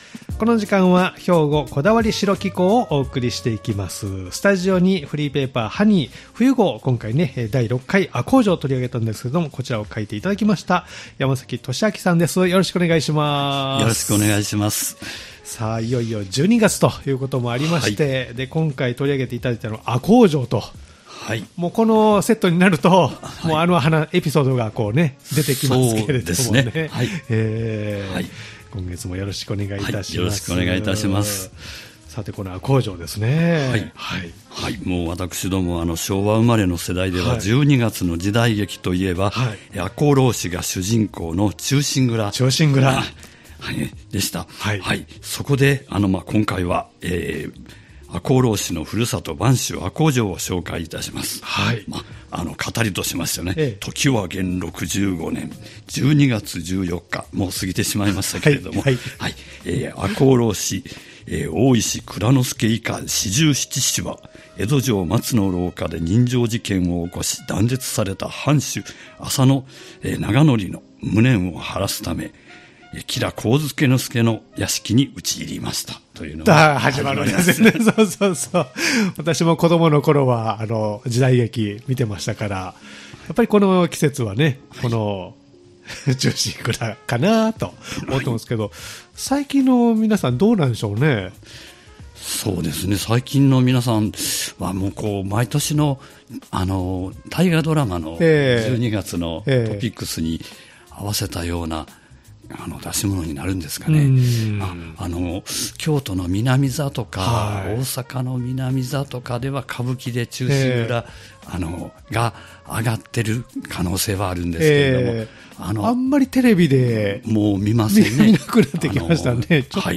年4回発行しているハニーFMのフリーペーパー「HONEY」、誌面では伝えきれない情報やエピソードをお聞きするポッドキャスト番組です。